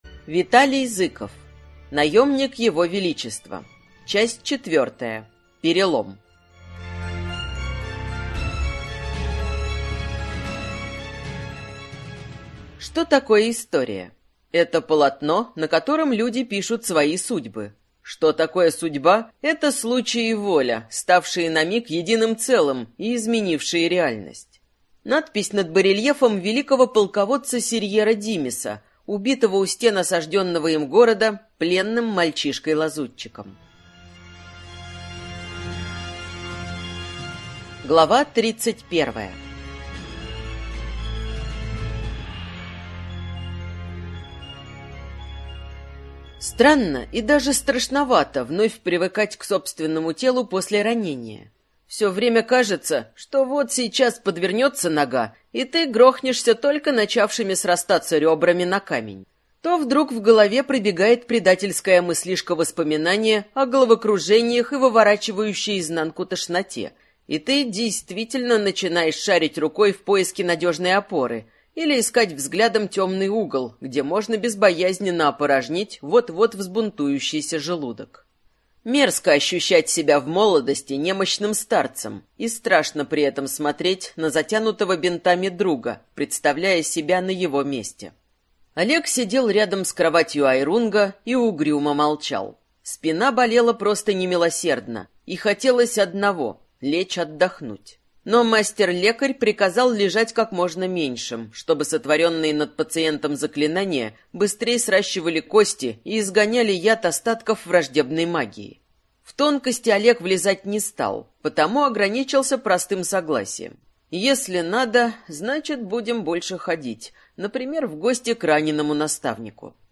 Аудиокнига Наемник Его Величества. Часть 4-я | Библиотека аудиокниг